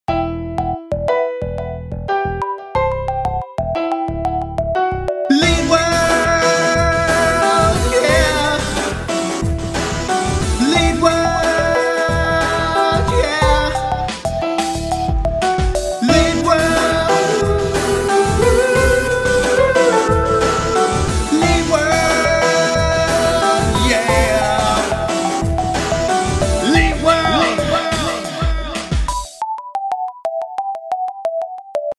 Great use of the theme underneath a pretty cool beat.